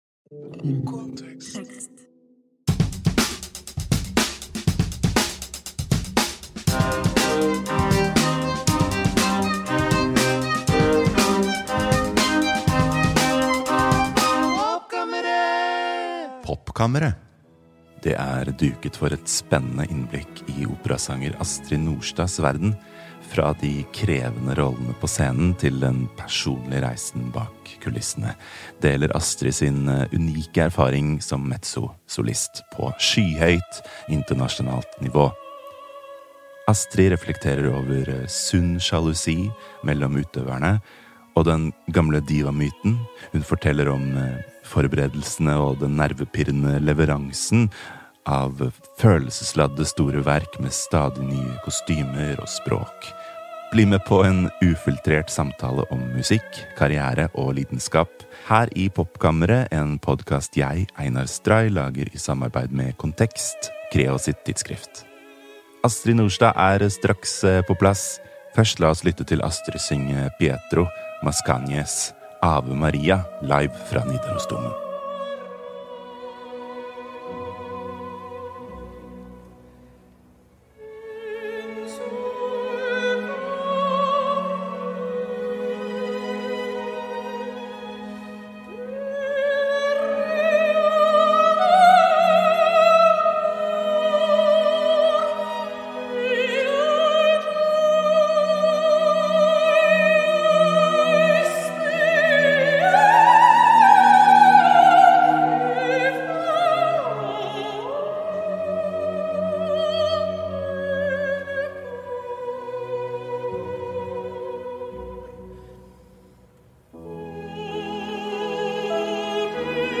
Bli med på en ufiltrert samtale om musikk, karriere og lidenskap.